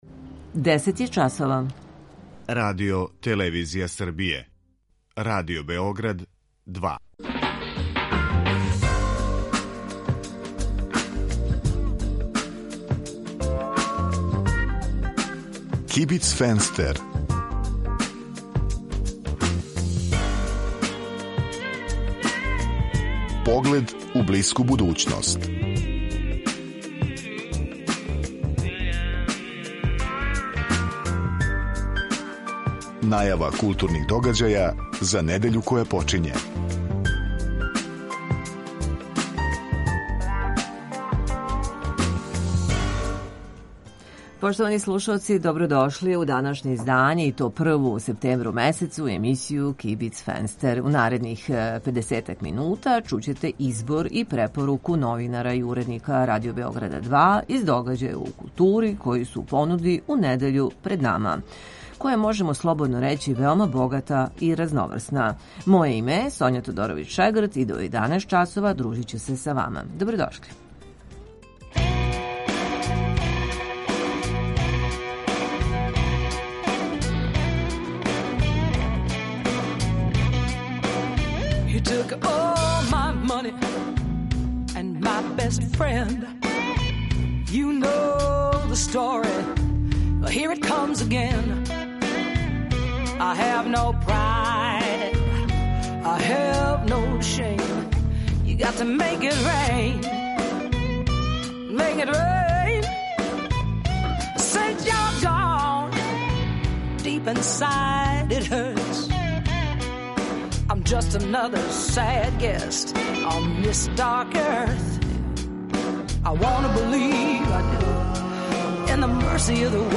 Осим тога чућете избор и препоруку новинара и уредника Радио Београда 2 из догађаја у култури који су у понуди у недељи пред нама.